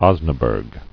[os·na·burg]